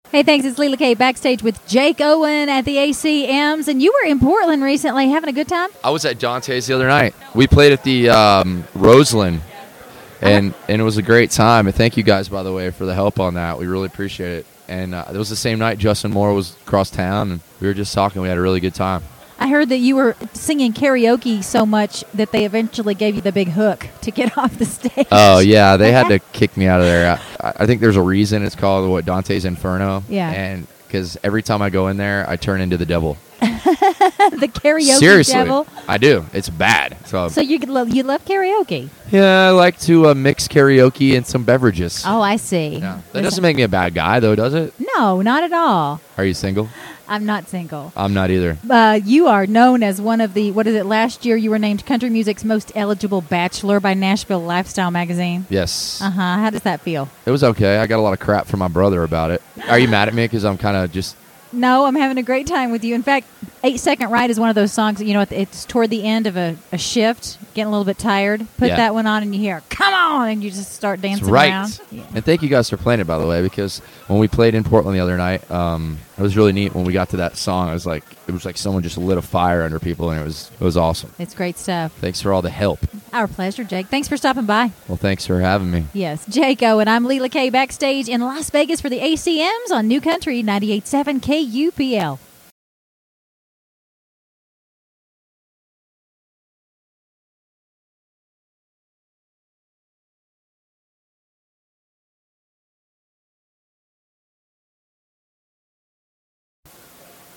Jake Owen Interview, 2010 ACM Awards